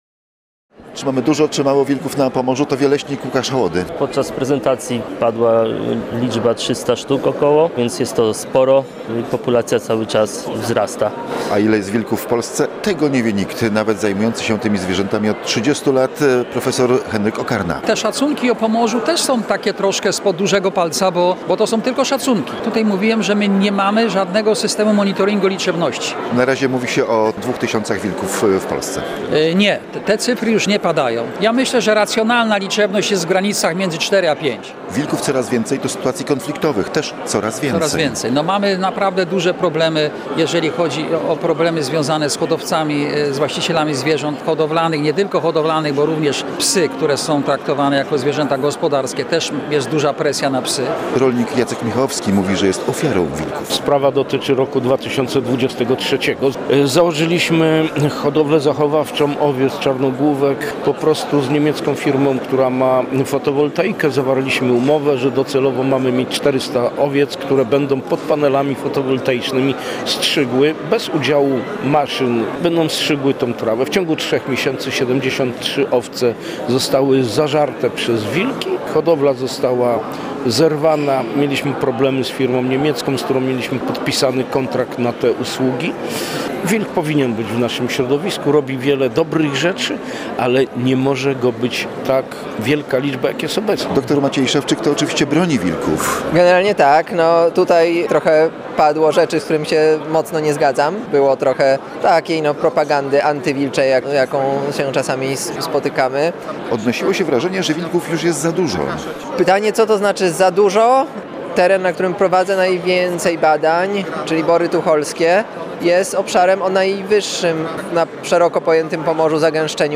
Konfliktów związanych z wilkami będzie coraz więcej. Populacja tych drapieżników w Polsce i na Pomorzu szybko rośnie – mówiono o tym na konferencji „Wilki blisko nas” zorganizowanej przez Uniwersytet Gdański i Regionalną Dyrekcję Lasów Państwowych w Gdańsku.